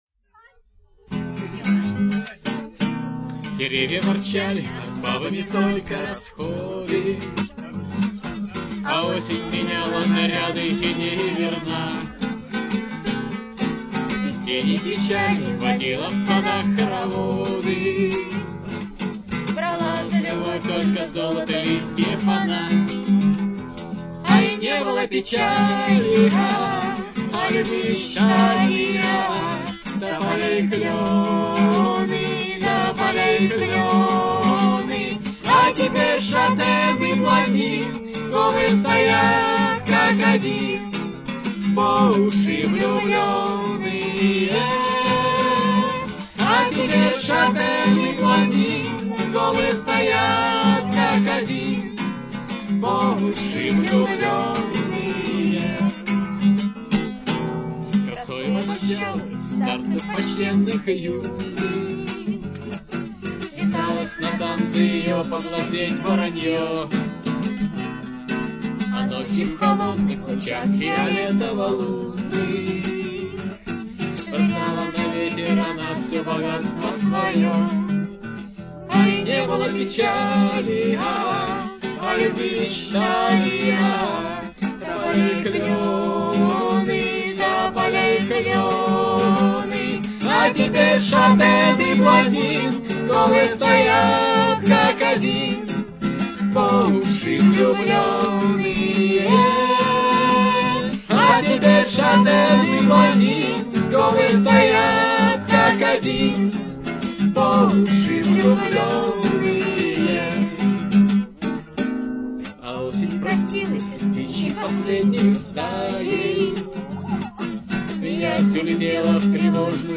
Сцена